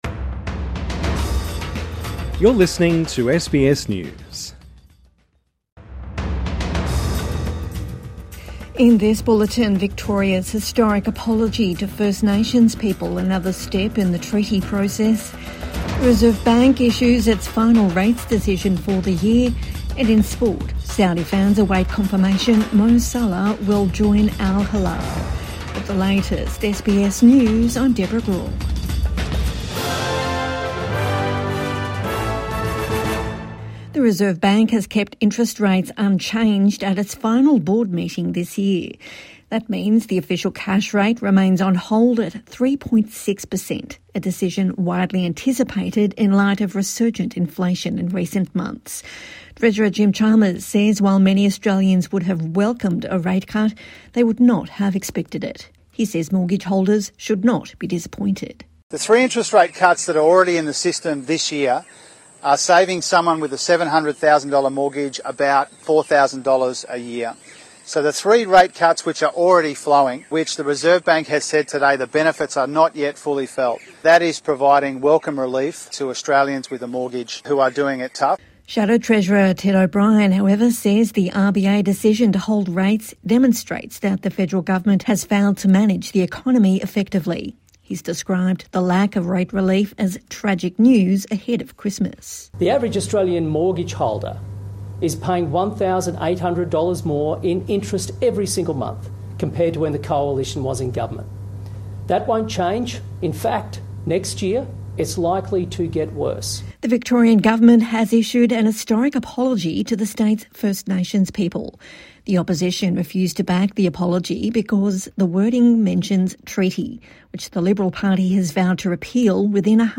Reserve Bank leaves rates unchanged ahead of Christmas | Evening News Bulletin 9 December 2025